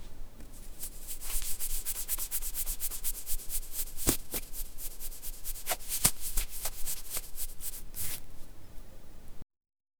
Cleaning Sound Effects - Free AI Generator & Downloads
frottement-dune-brosse-ne-6esna73n.wav